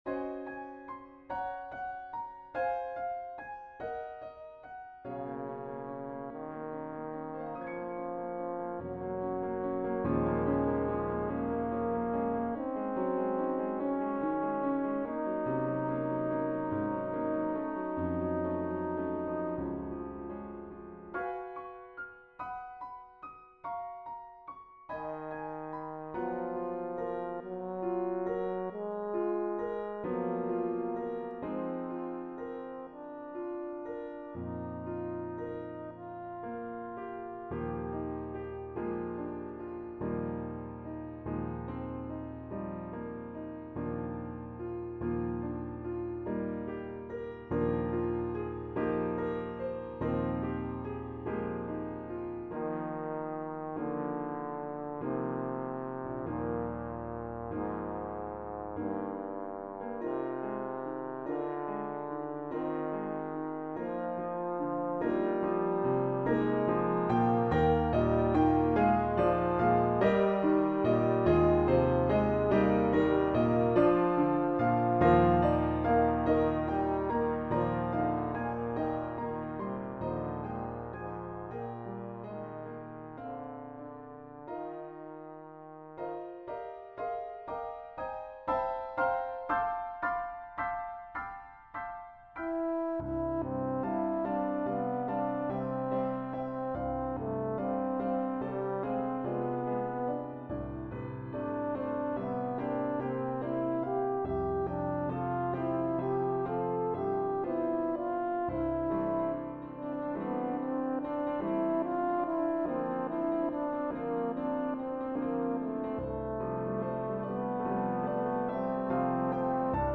for Piano and Horn in F.